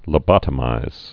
(lə-bŏtə-mīz, lō-)